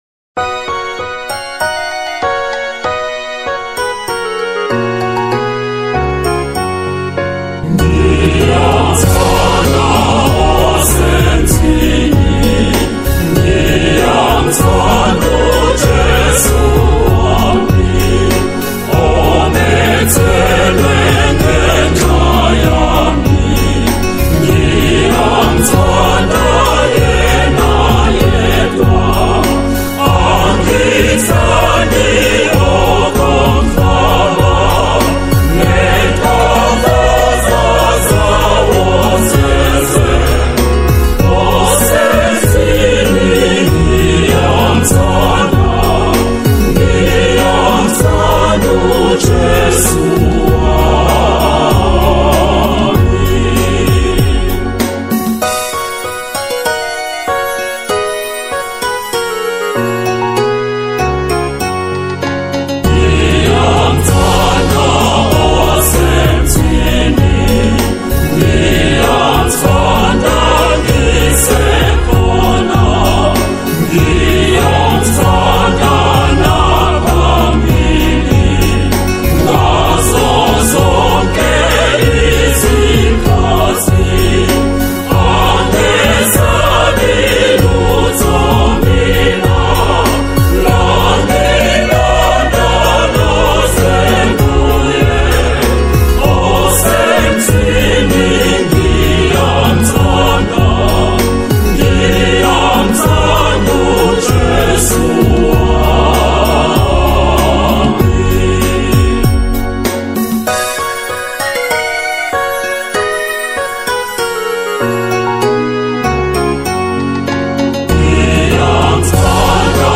Catholic Zulu hymns